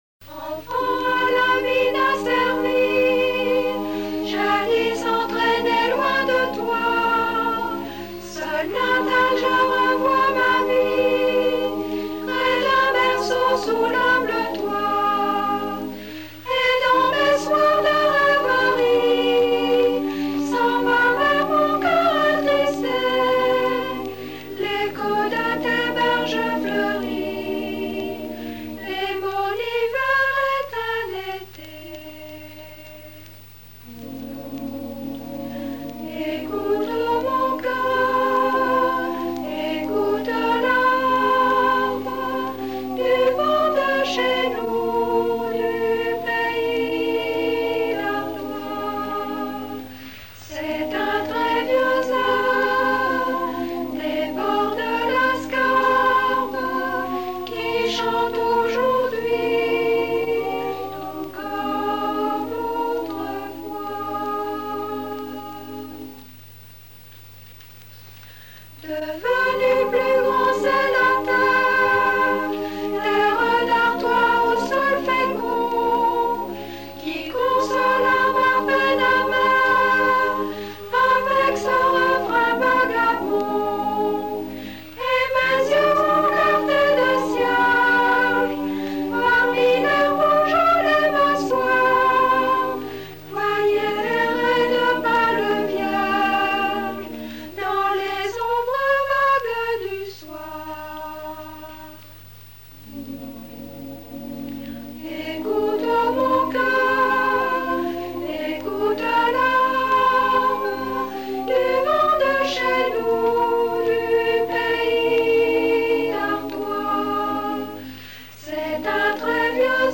Présentation du musée du château de Noirmoutier
Pièce musicale inédite